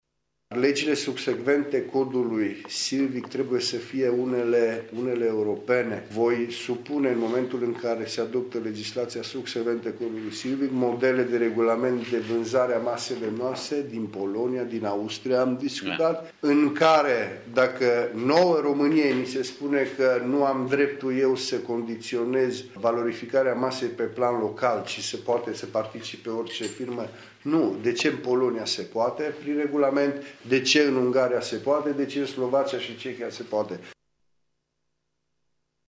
Declaraţia aparţine deputatului PNL Cristian Chirteş şi a fost făcută în contextul discuţiilor legate de modificarea Codului Silvic.